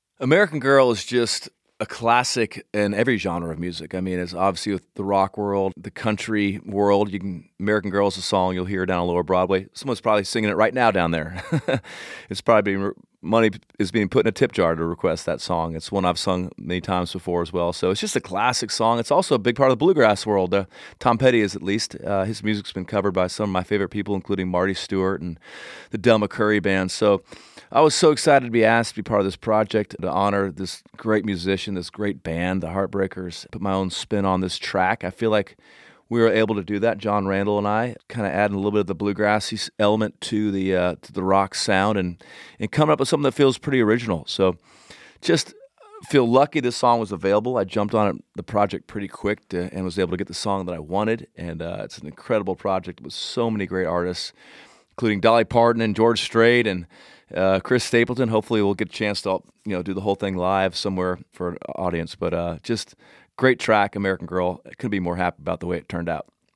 Dierks Bentley talks about recording "American Girl" for the new Tom Petty tribute project.